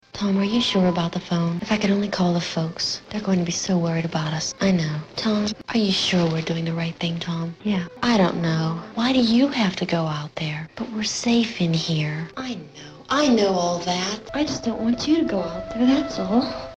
Audio snippets assembled from Night of the Living Dead (1968).
Night-of-the-Living-Dead-1968-woman.mp3